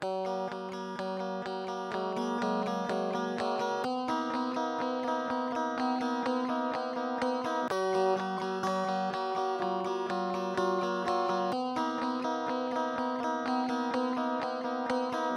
90年代的STRATOCASTER墨西哥ARPEGGIO
Tag: 125 bpm Grunge Loops Guitar Electric Loops 2.58 MB wav Key : Unknown